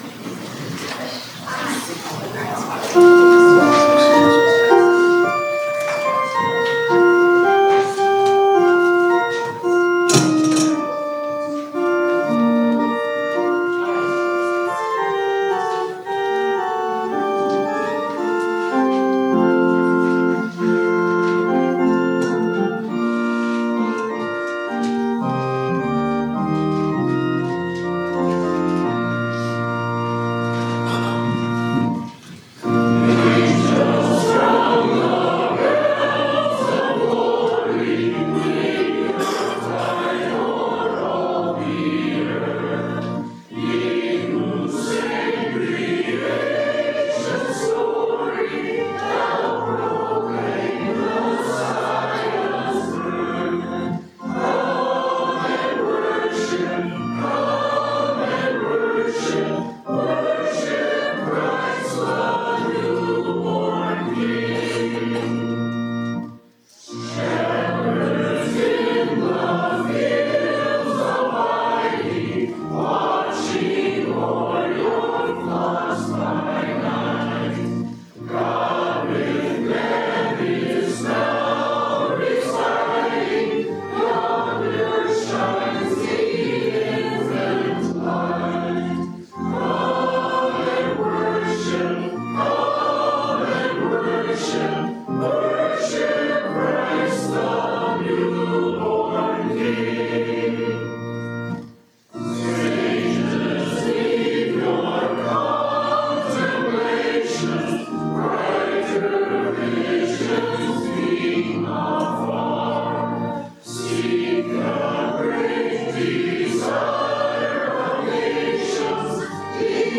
Zion Worship Dec 29th, 2024